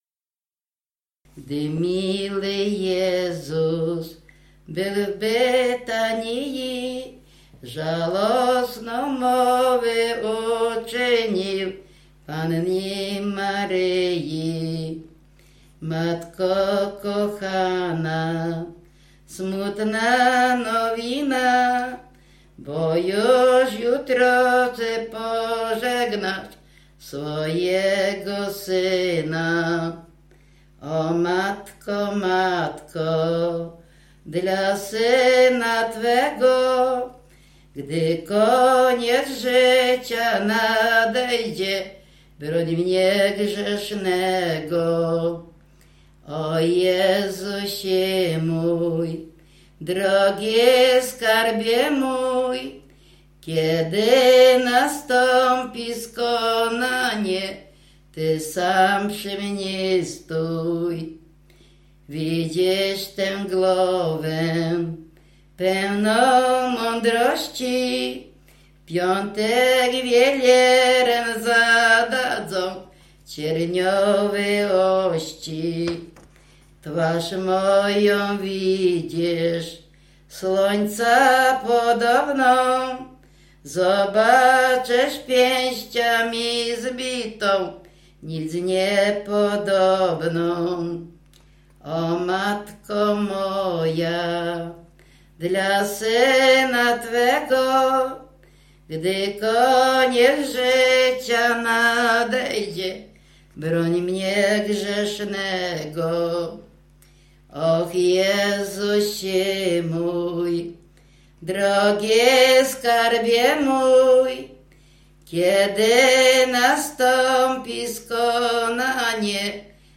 W wymowie Ł wymawiane jako przedniojęzykowo-zębowe;
Wielkopostna
Array wielkopostne katolickie nabożne wielkanocne